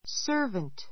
servant sə́ː r vənt サ ～ヴァン ト 名詞 ❶ 召使 めしつか い, 使用人; 用務員 ⦣ 性別に関係なく使う. ❷ （市民・国民への） 奉仕 ほうし 者, 公務員 ⦣ ふつう public [civil] servant という形で使われる.